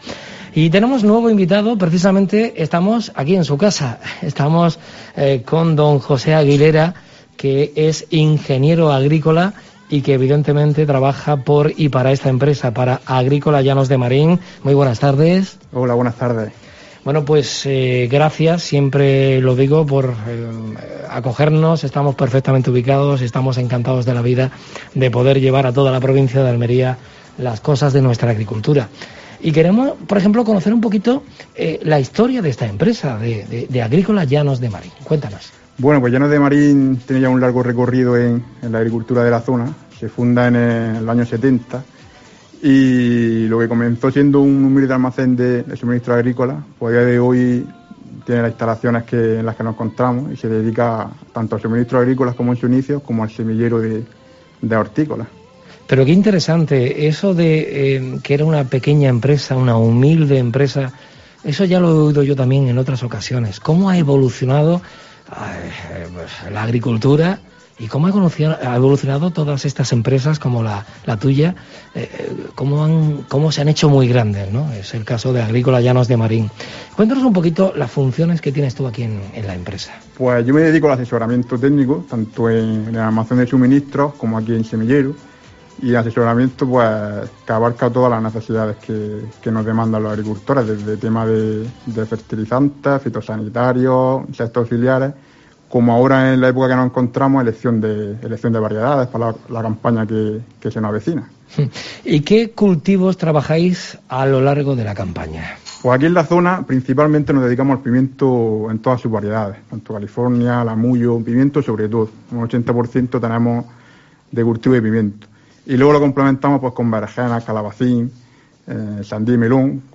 Agrícola Llanos de Marín ha sido el anfitrión en la tercera jornada de la I Semana de la agricultura en Roquetas de Mar, organizada por COPE Almería.